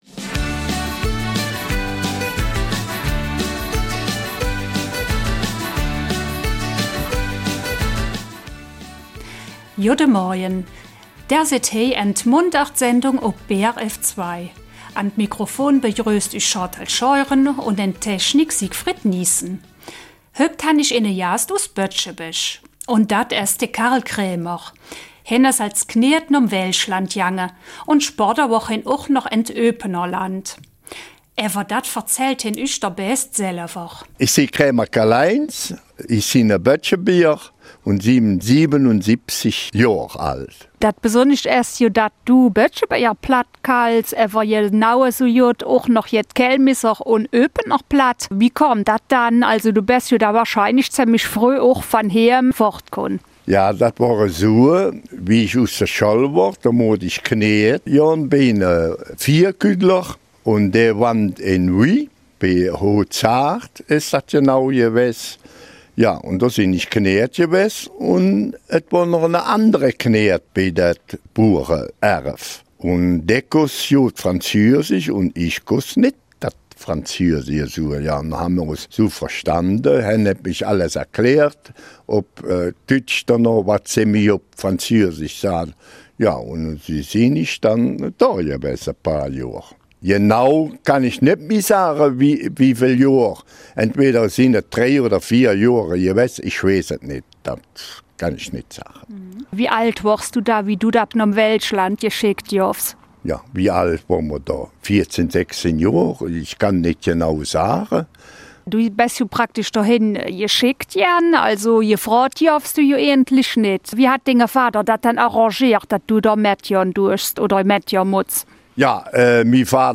Später zog es ihn nach Eupen, Gemmenich und Kelmis, wo er neben seinem Heimatdialekt auch das dortige Platt sprach.
Eifeler Mundart